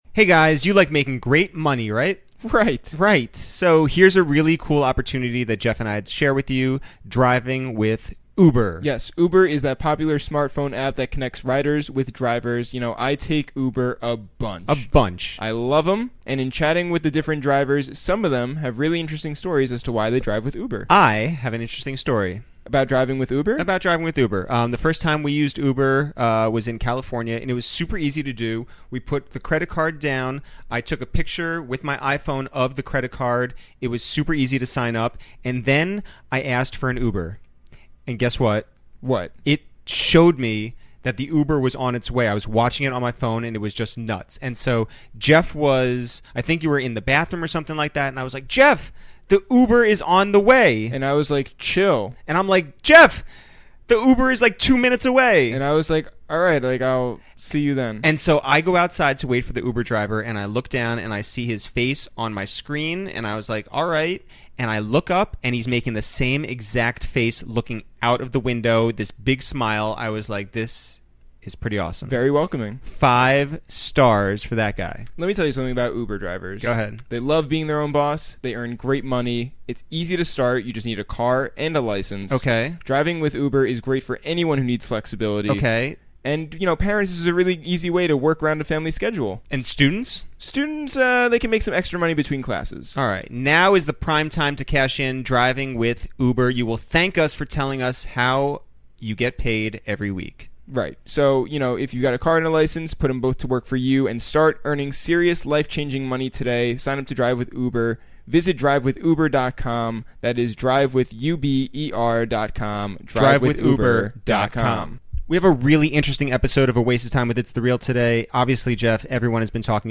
we invited two great personalities over to our apartment